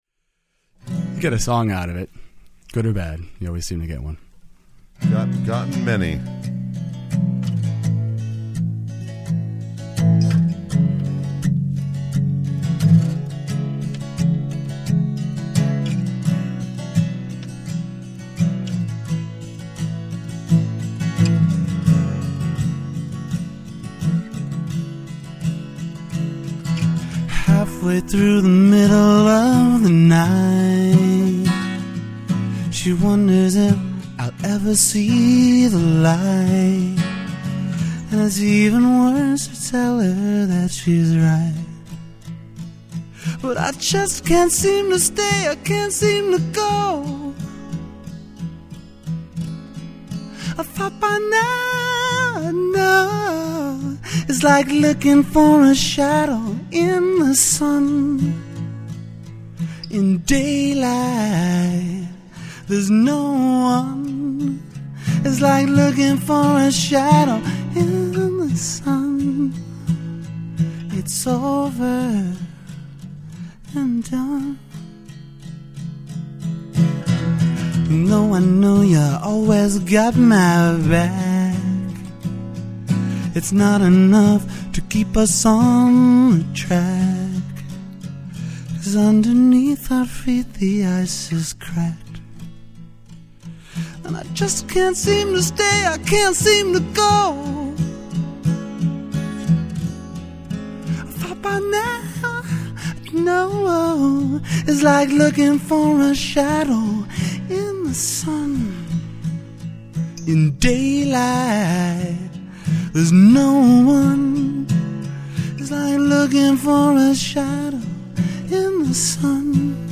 with host Pat DiNizio (of The Smithereens)